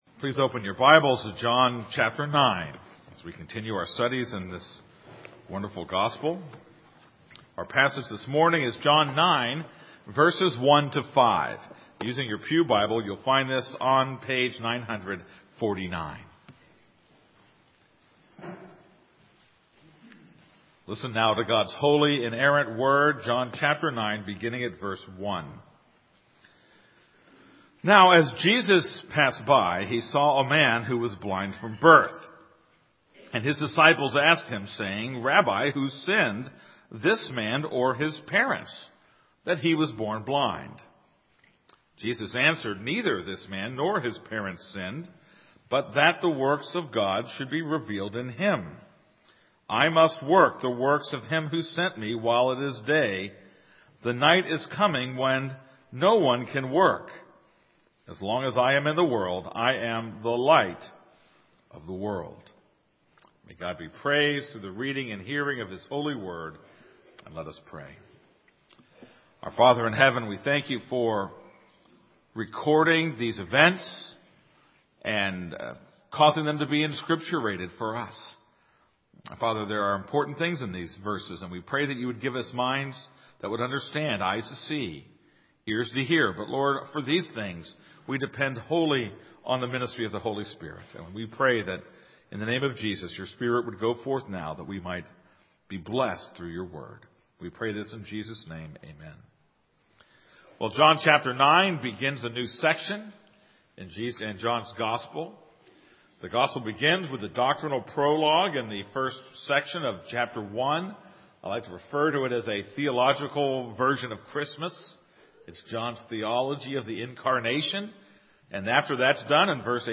This is a sermon on John 9:1-5.